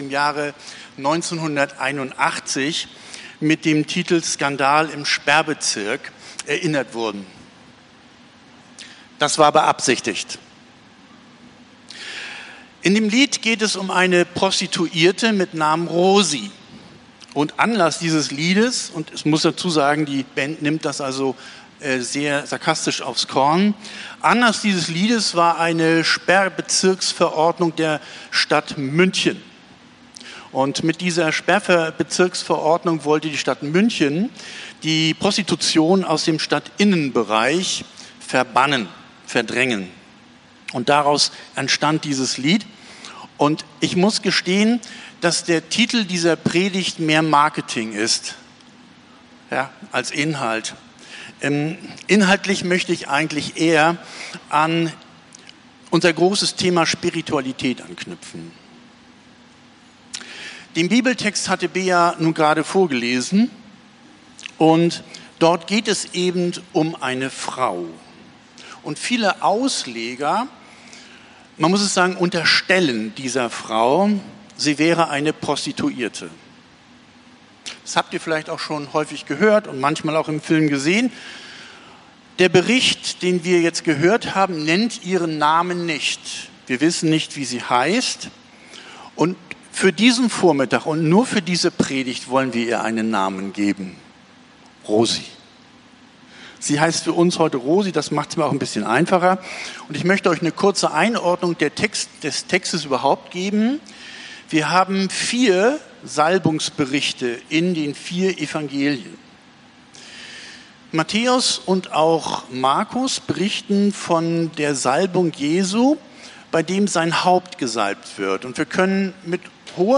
Predigt vom 29.06.2025